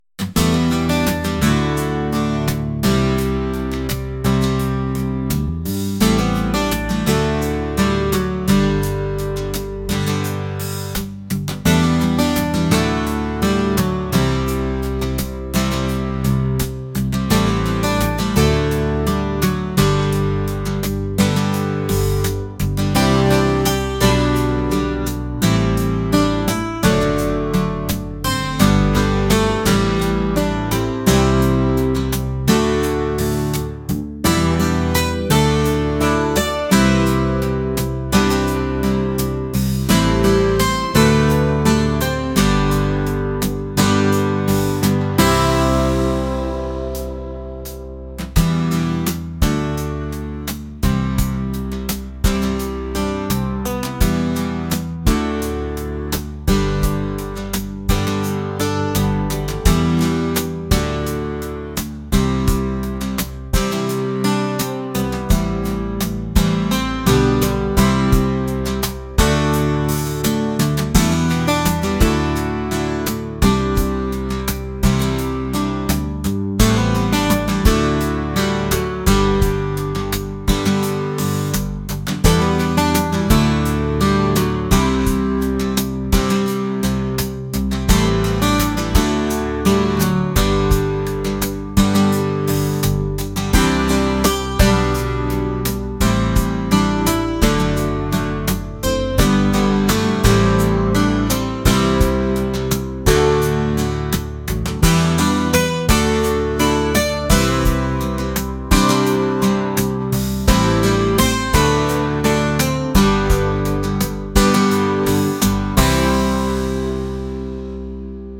acoustic | laid-back | folk